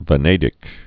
(və-nādĭk, -nădĭk)